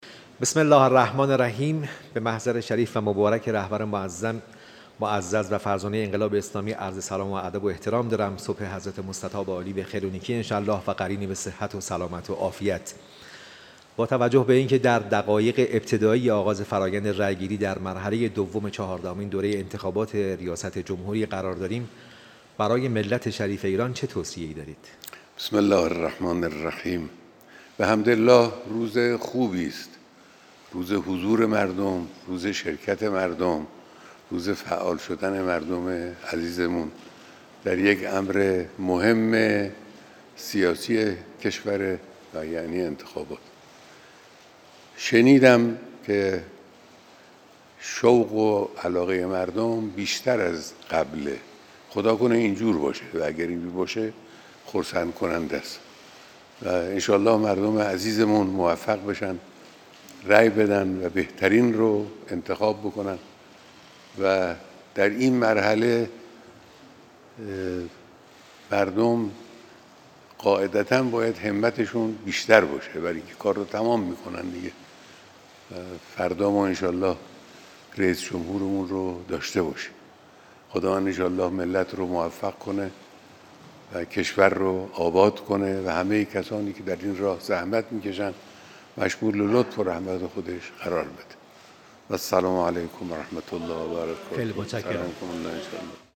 بیانات پس از حضور در دور دوم چهاردهمین دوره انتخابات ریاست جمهوری